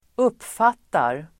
Uttal: [²'up:fat:ar]